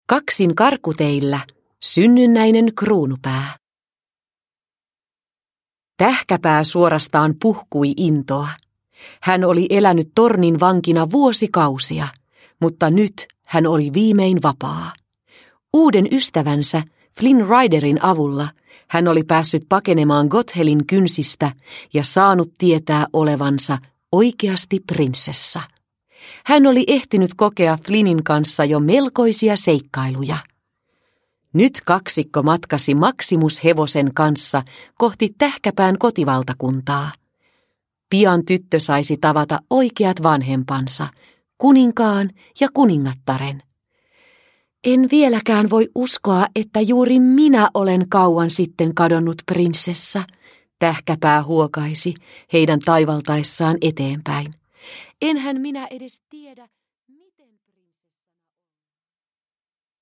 Disney Prinsessat. Synnynnäinen kruunupää – Ljudbok – Laddas ner